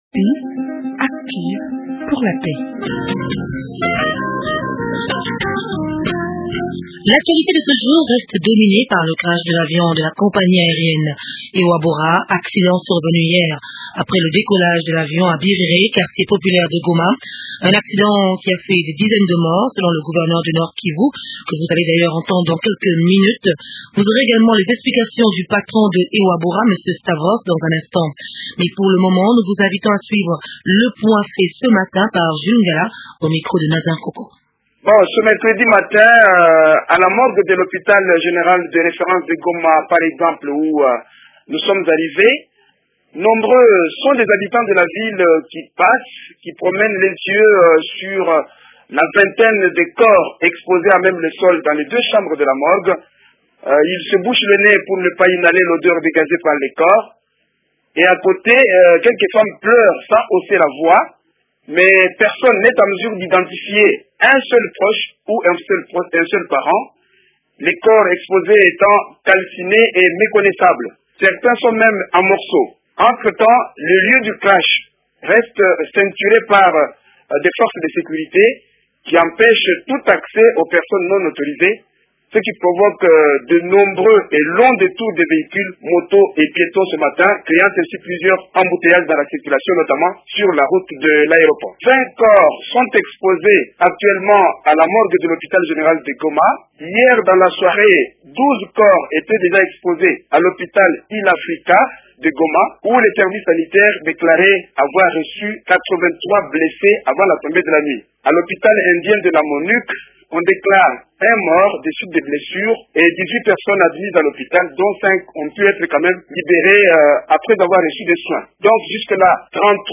Julien Paluku,le gouverneur du Nord Kivu